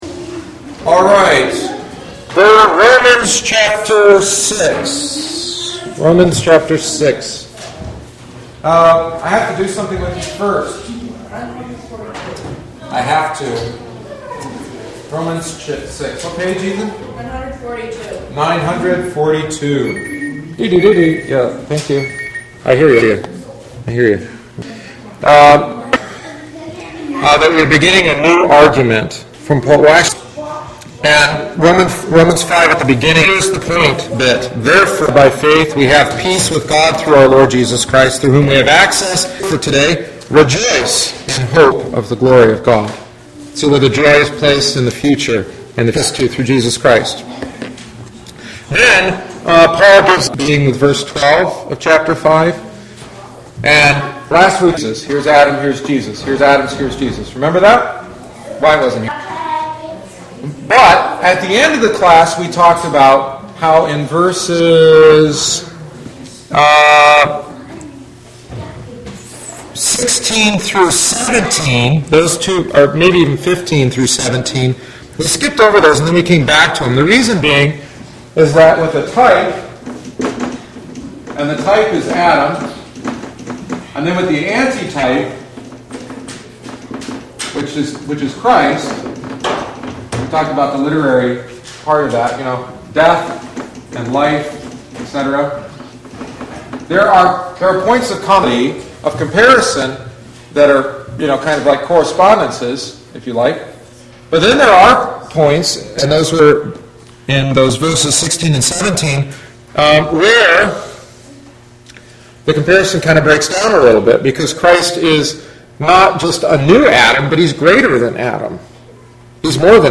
The following is the fourteenth week’s lesson. For a brief interlude, we considered the Christian life according to St. Paul in Romans chapters 6-8.
Apologies for the audio quality.